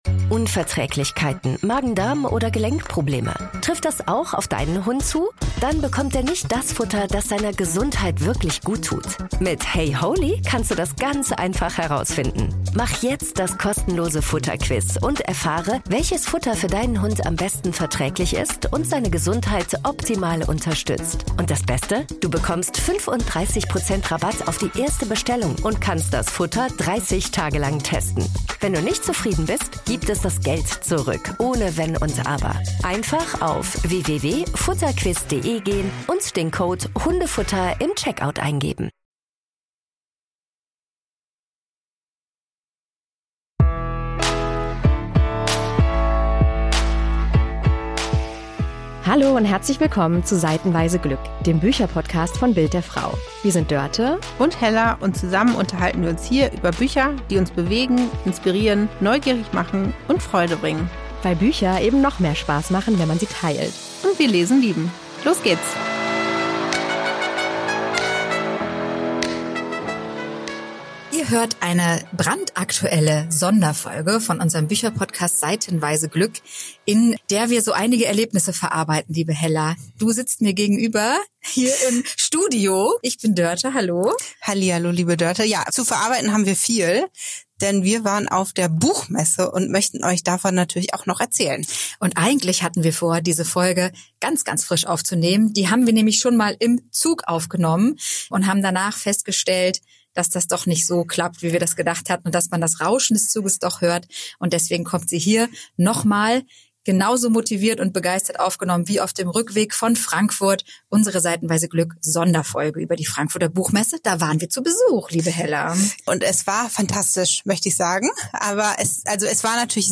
#106 Sonderfolge: Auf der Frankfurter Buchmesse – Betriebsausflug ins Leseglück ~ Seitenweise Glück Podcast